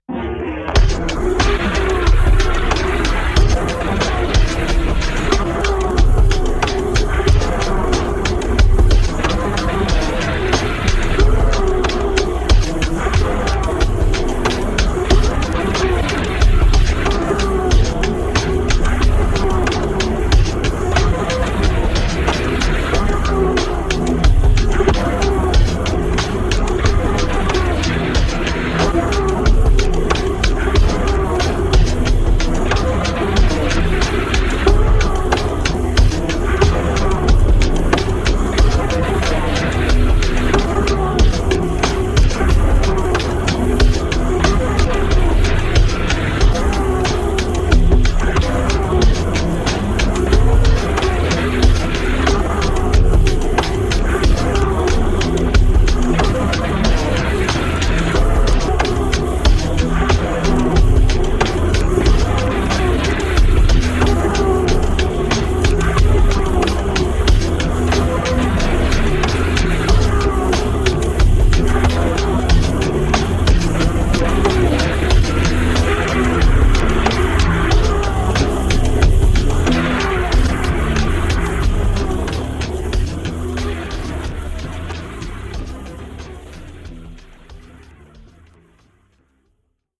Electronix Techno Ambient